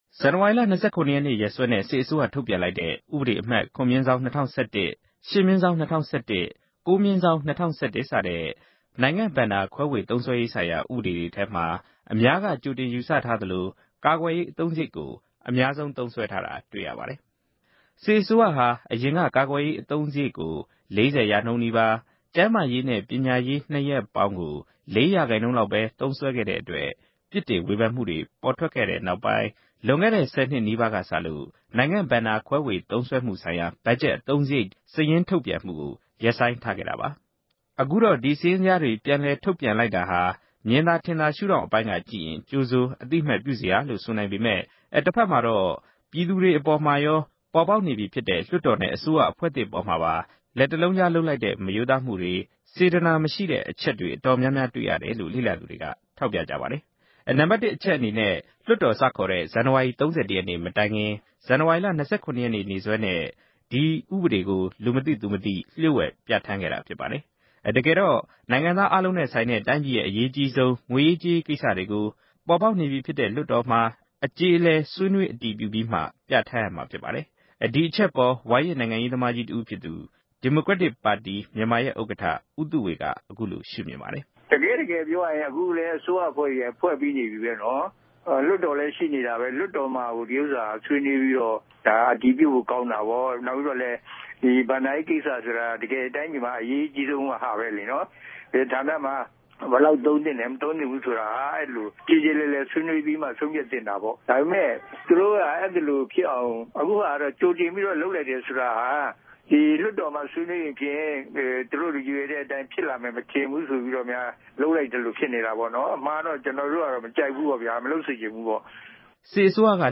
စုစည်းတင်ပြချက်